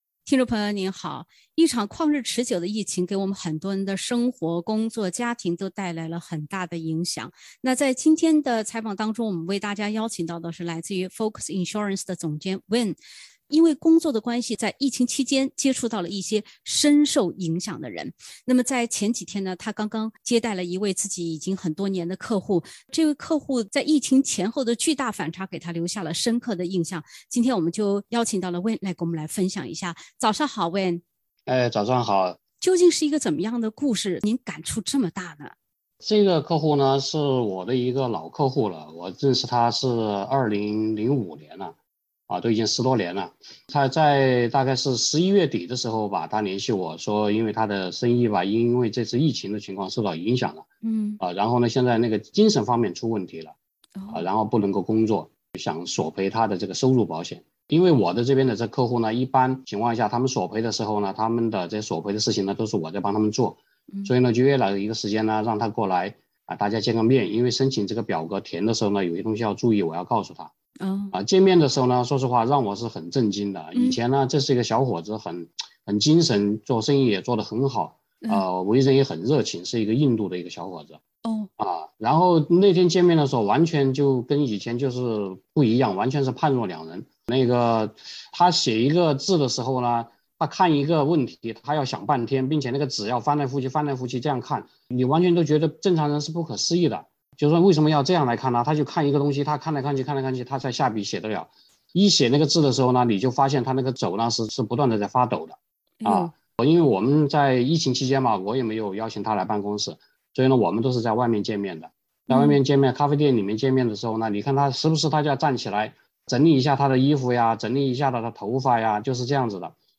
保险从业人员讲述老客户疫情前后巨大变化。